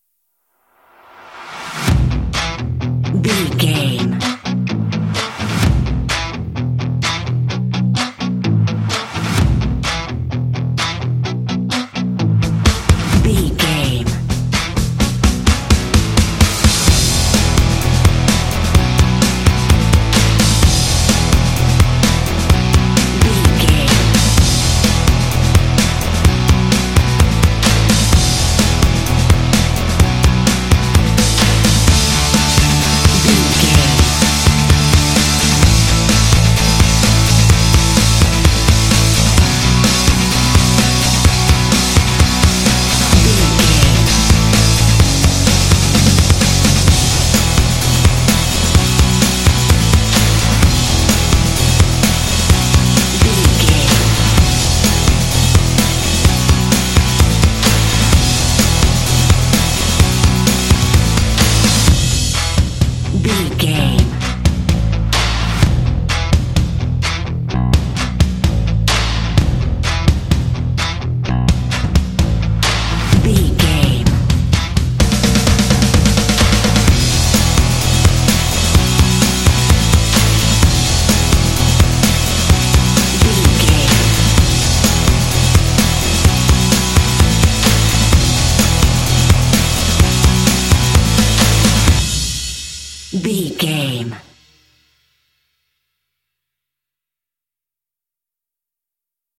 This indie track contains vocal “hey” shots.
Ionian/Major
lively
cheerful/happy
drums
bass guitar
electric guitar
percussion
synth-pop
alternative rock
indie